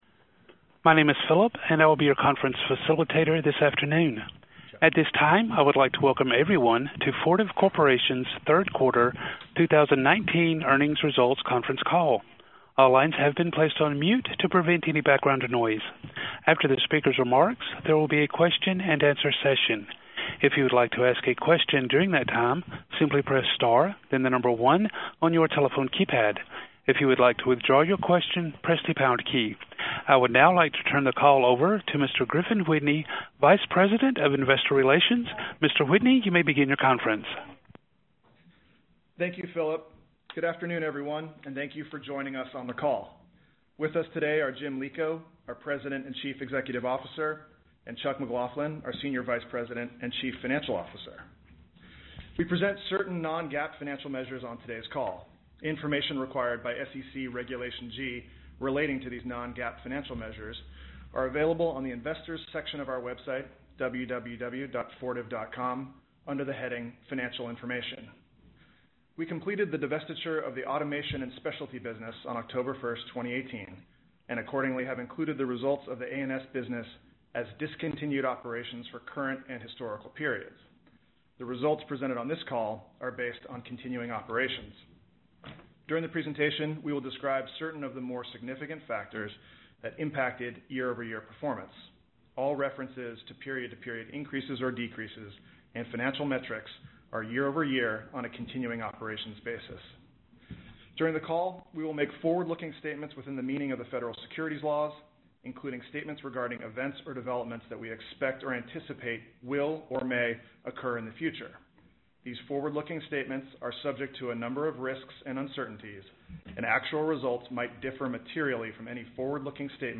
Earnings Webcast Q3 2019 Audio
Q319_Earnings_Call_Replay.mp3